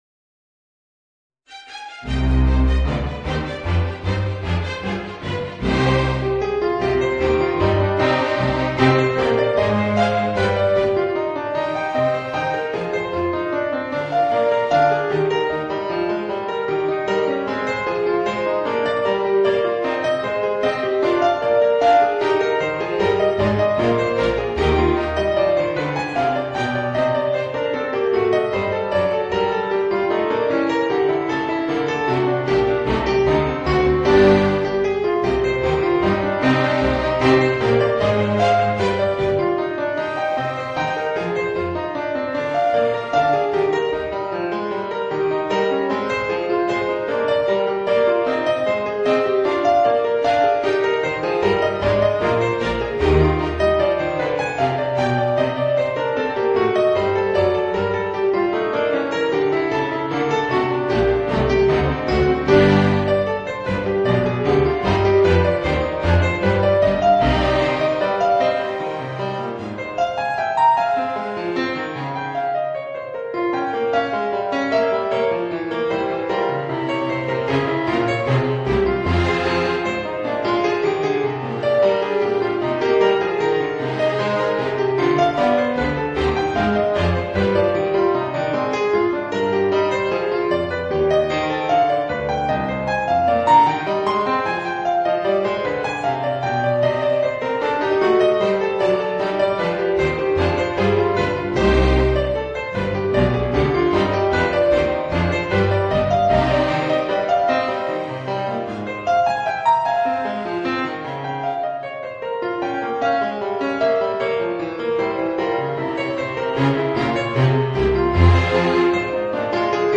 Voicing: Piano and String Orchestra